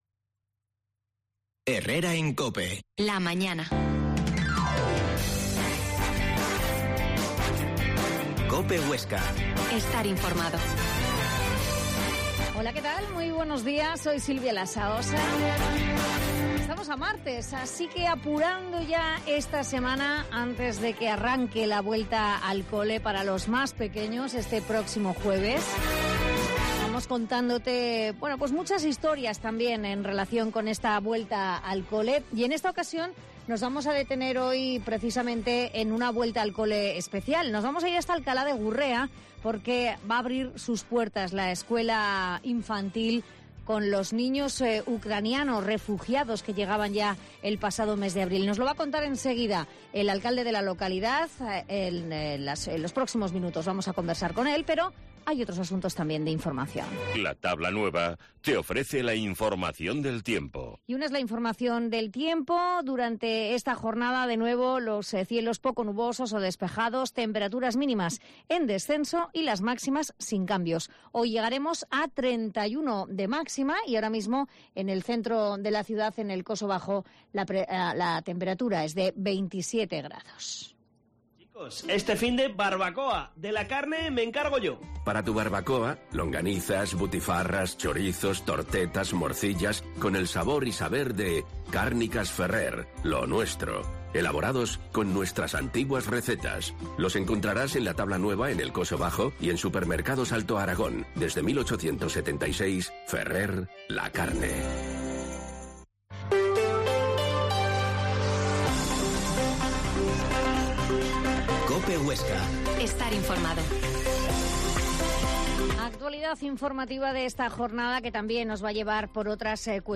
Herrera en COPE Huesca 12.50h Entrevista a Jossechu Marín, alcalde de Alcalá de Gurrea